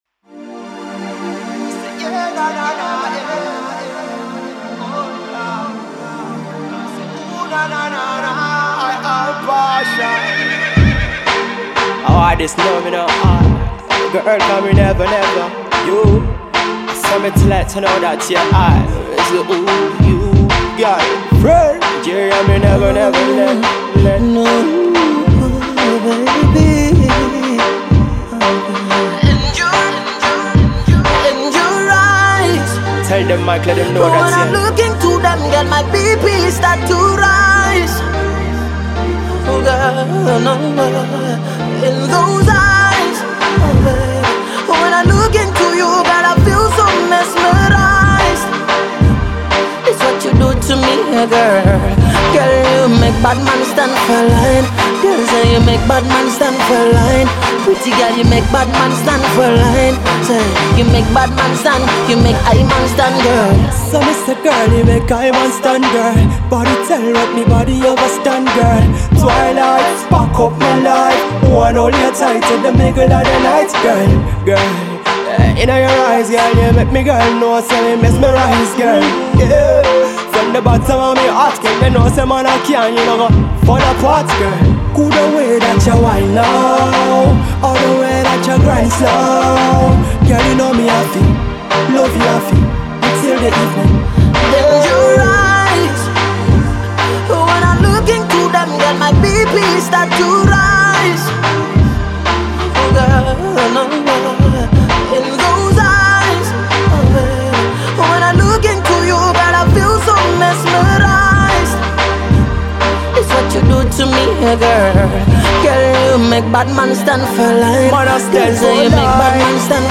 With a nice mix of Reggae, DanceHall and Afro Pop